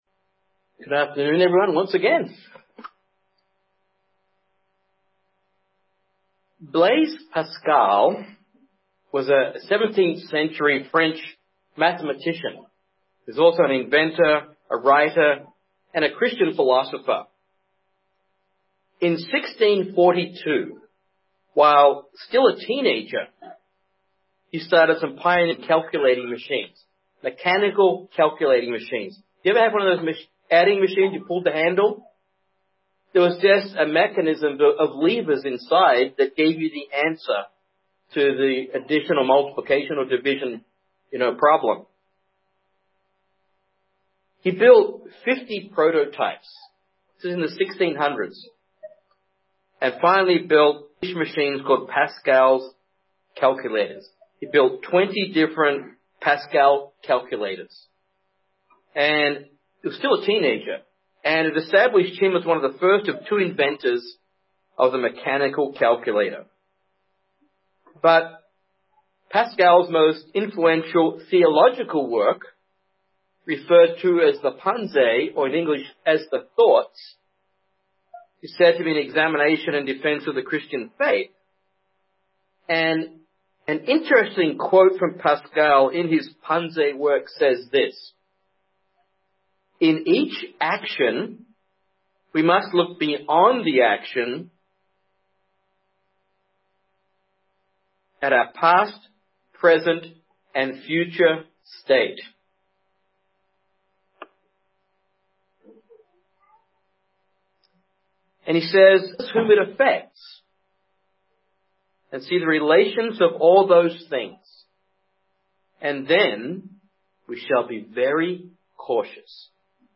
Our actions affect the lives of other people and we should be very cautious. This sermon examines how the actions of one individual—which appeared to affect only him—in reality affected the entire nation.